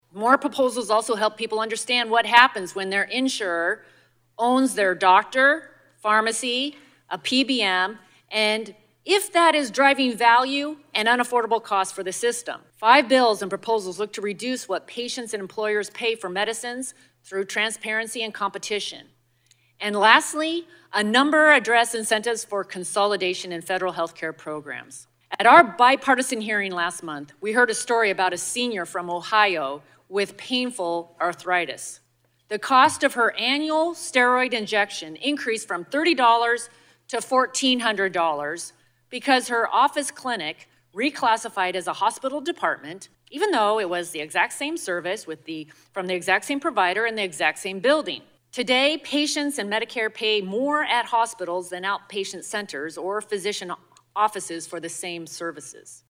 WASHINGTON, DC – Eastern Washington Congresswoman Cathy McMorris Rodgers (WA-05) delivered opening remarks today at the Health Subcommittee hearing on the need to improve healthcare price transparency and increase competition to help lower costs for patients nationwide.